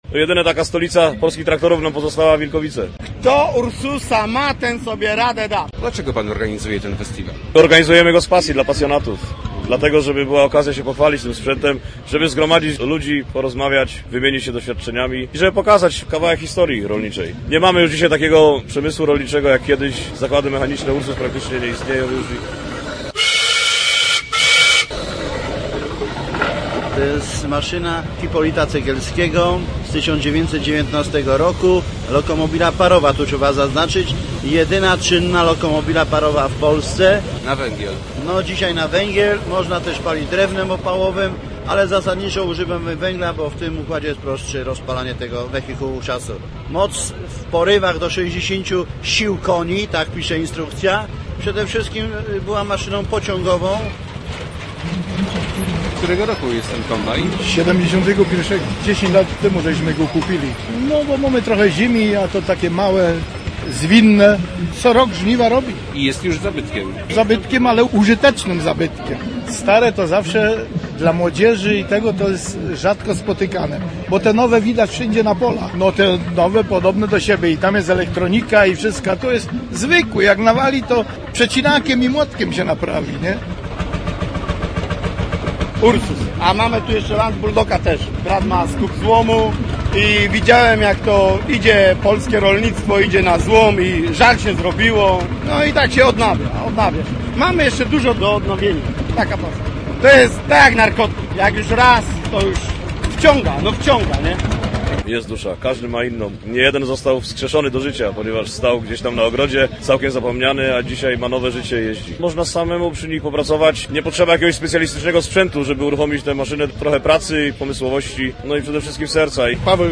W Wilkowicach trwa X Festiwal Starych Ciągników i Maszyn Rolniczych. Do tej podleszczyńskiej wsi przyjechali pasjonaci z całej Polski, przywieźli ponad 200 eksponatów, co jest rekordem festiwalu.
oi8u1bu2mngbe40_traktory_ciagniki_zlot.mp3